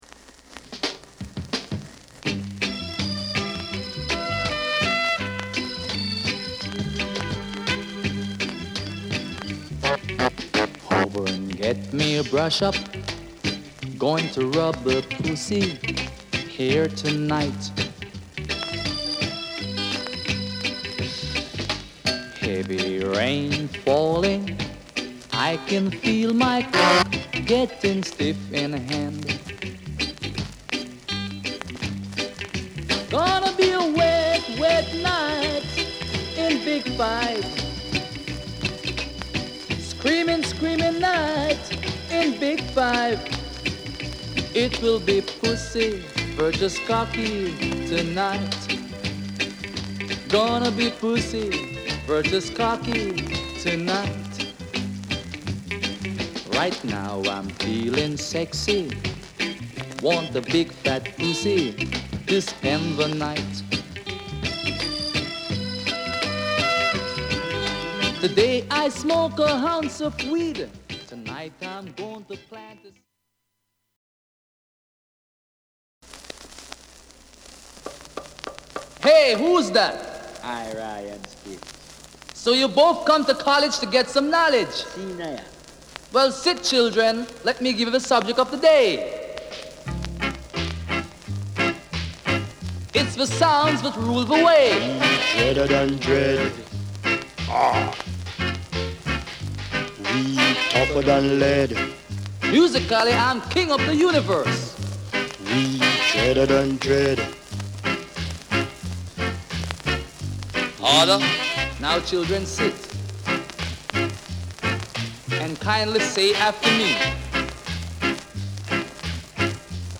Genre: Ska / Rocksteady ストレートなSKAから洗練されたソウルフル・ナンバーまで。